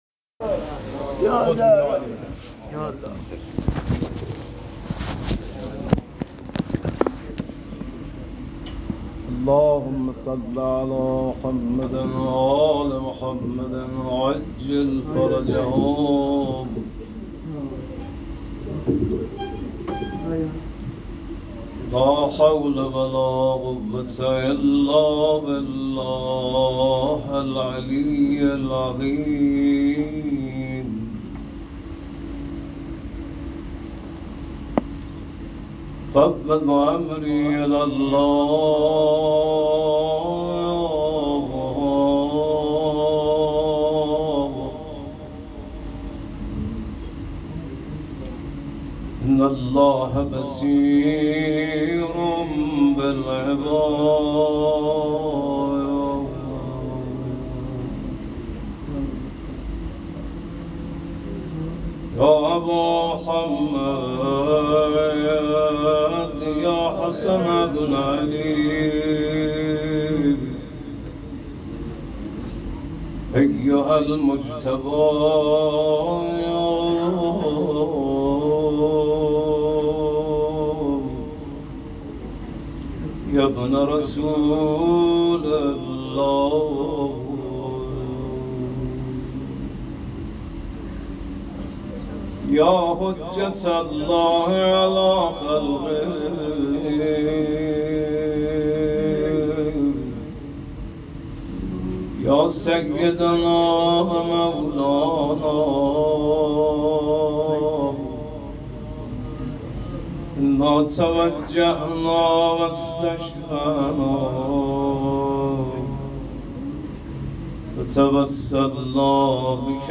روضه شب پنجم محرم